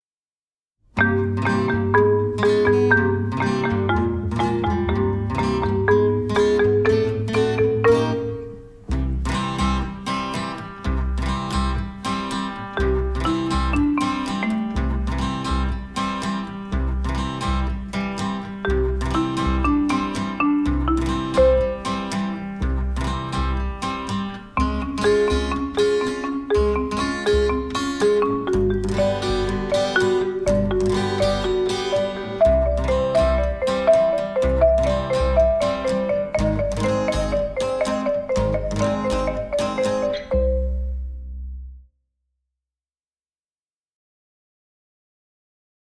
Music from the original motion picture soundtrack
Recorded at Stair 7 Studios
drums, percussion
trumpet
trombones